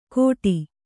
♪ kōṭi